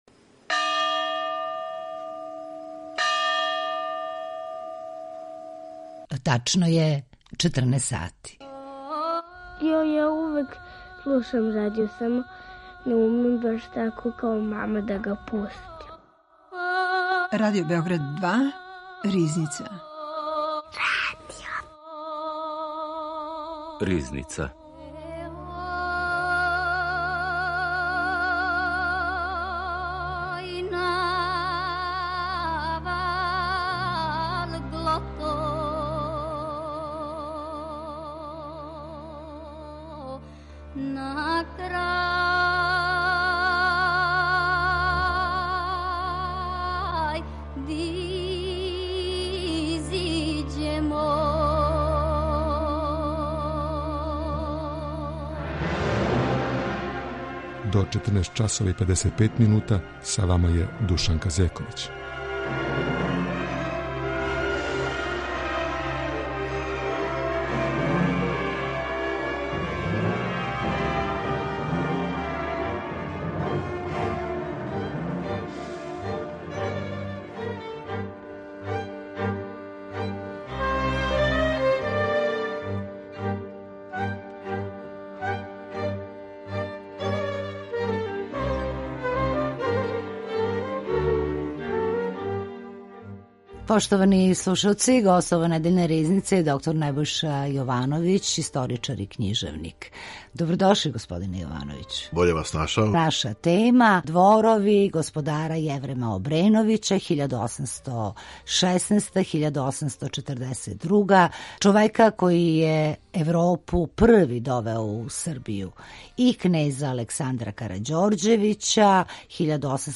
Гост Ризнице